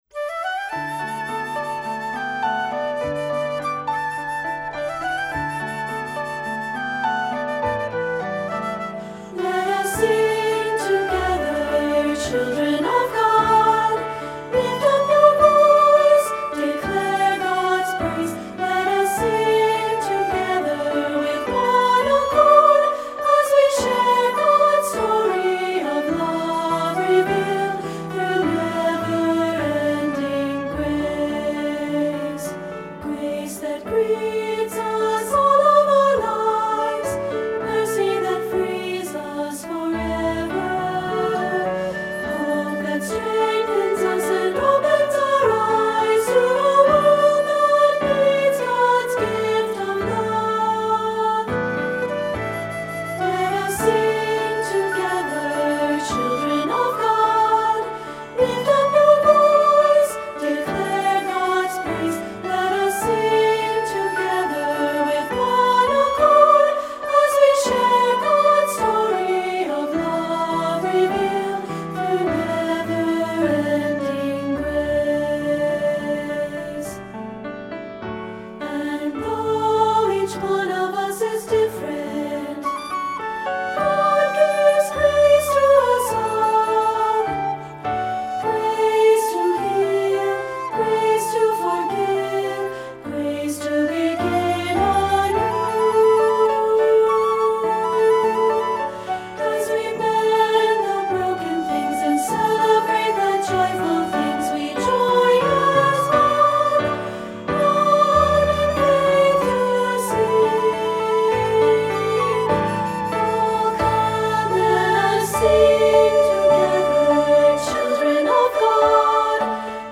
Unison/two-part with piano and optional flute